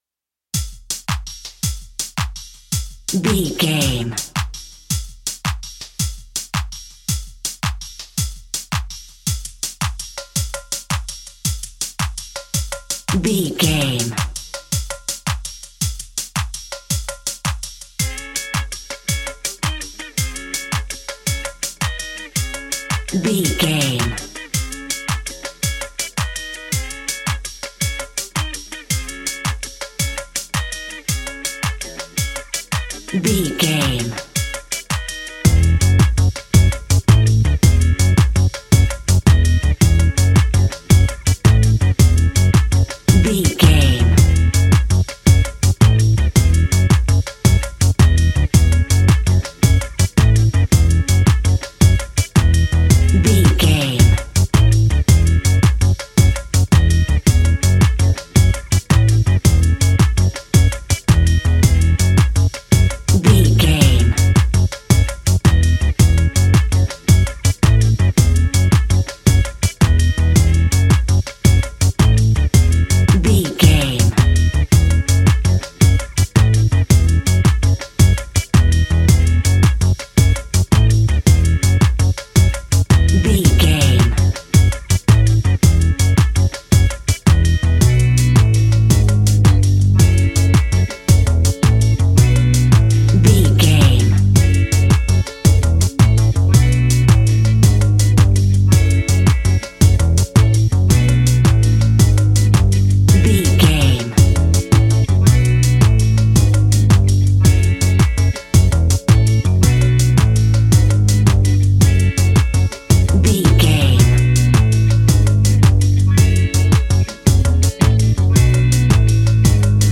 Aeolian/Minor
G♭
funky
uplifting
bass guitar
electric guitar
organ
drums
saxophone